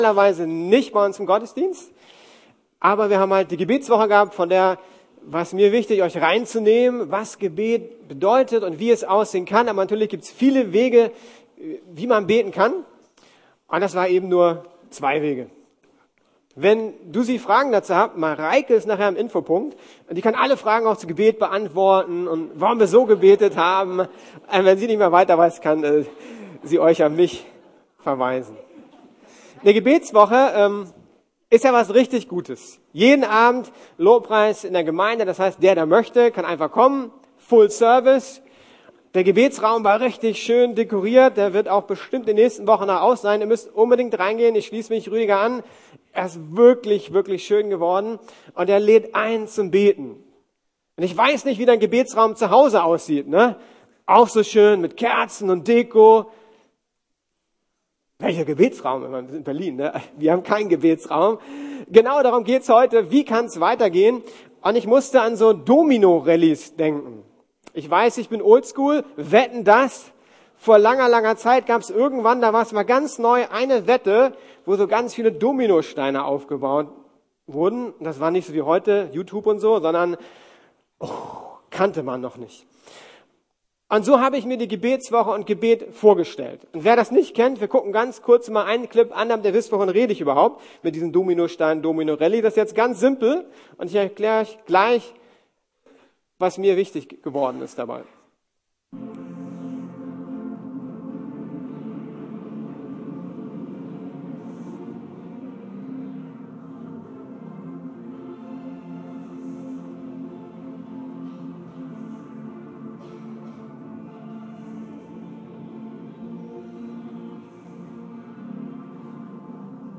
Explosive Kraft ~ Predigten der LUKAS GEMEINDE Podcast